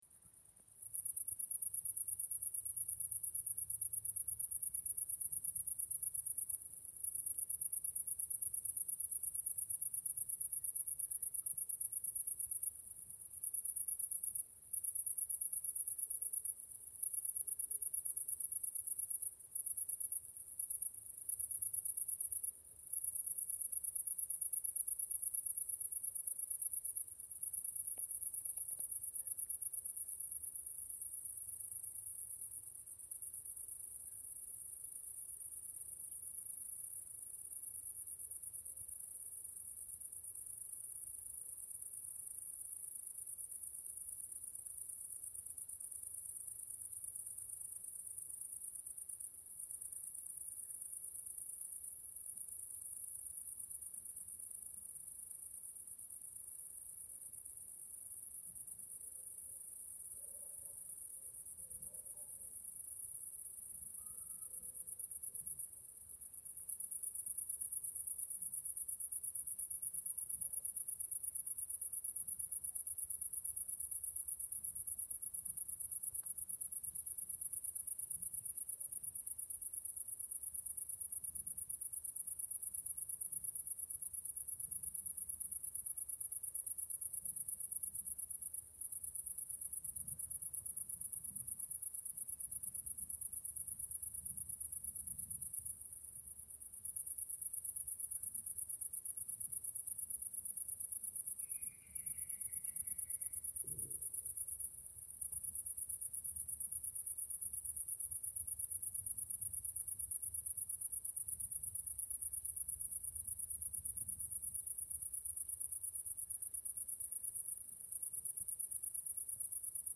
Стрекотание сверчков ночью и вечером на фоне природы в mp3
14. Звук сверчков вечером в пшеничном поле для фона
sverchki-v-pole.mp3